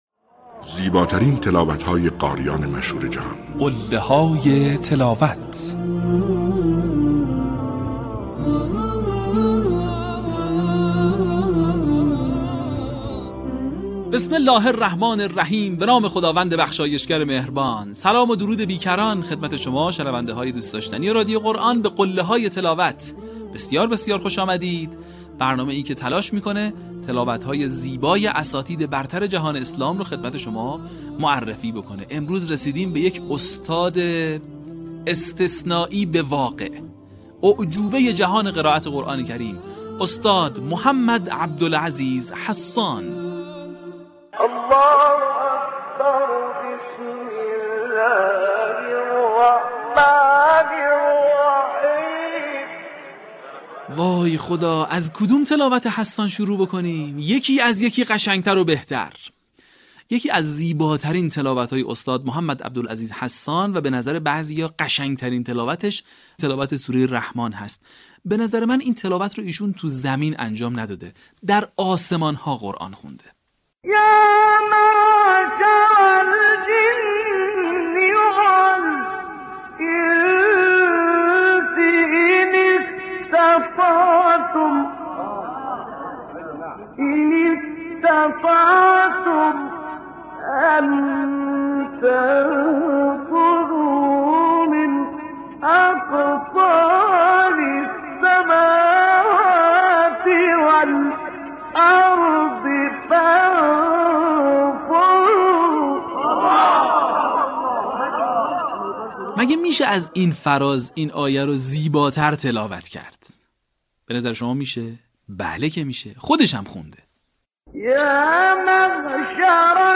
صوت | پنج فراز ماندگار از محمد عبدالعزیز حصان
به همین منظور برترین و برجسته‌ترین مقاطع از تلاوت‌های شاهکار قاریان بنام جهان اسلام که مناسب برای تقلید قاریان است با عنوان «قله‌های تلاوت» ارائه و بازنشر می‌شود. در قسمت دهم، فرازهای شنیدنی از تلاوت‌های به‌یاد ماندنی استاد محمد عبدالعزیز حصان را می‌شنوید.